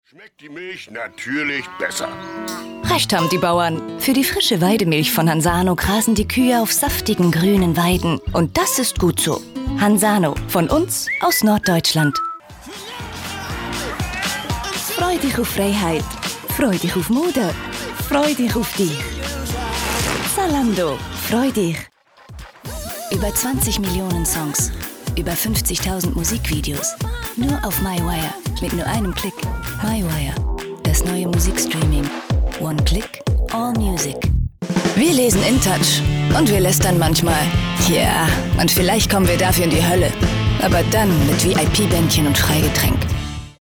Fundiert ausgebildete Sprecherin, Schauspielschule, Native Speaker auch für Schweizer Dialekte, Sprecherin, mittel bis tiefe Stimme, Hamburg, Schweiz, TV- und Radio Spots, Voice Over, Imagefilme, Industriefilme, E-Learnings, Synchron, Computerspiele
Sprechprobe: Werbung (Muttersprache):